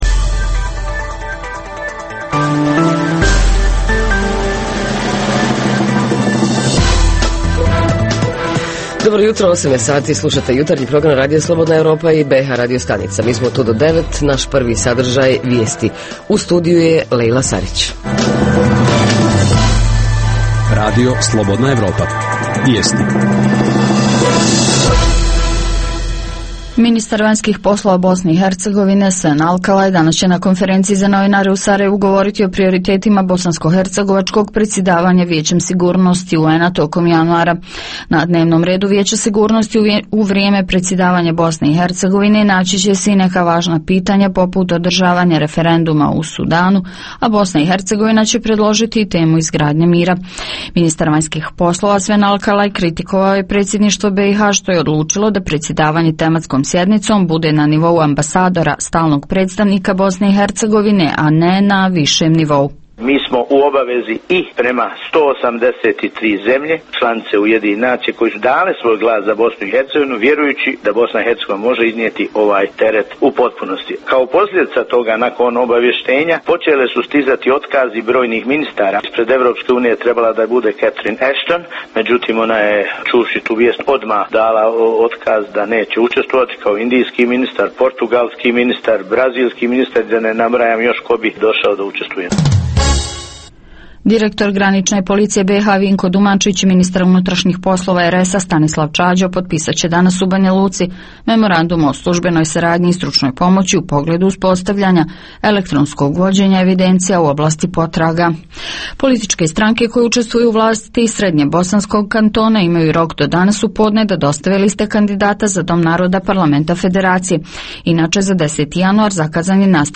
Jutarnji program za BiH koji se emituje uživo. Sadrži informacije, teme i analize o dešavanjima u BiH i regionu. Reporteri iz cijele BiH javljaju o najaktuelnijim događajima u njihovim sredinama.